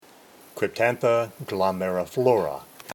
Pronunciation/Pronunciación:
Cryp-tán-tha glo-me-ri-flo-ra